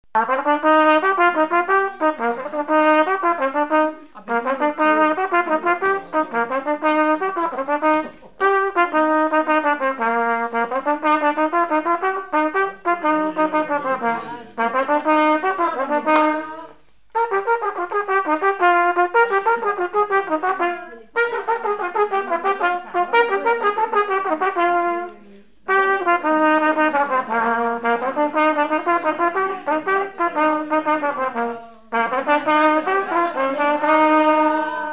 Avant-deux
Thème : 0125 - Chants brefs - De noces Résumé : Allez-vous-en les gens de la noce.
Catégorie Pièce musicale inédite